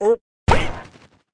Npc Duck Launch Sound Effect
npc-duck-launch.mp3